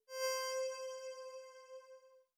SFX_Menu_Confirmation_07.wav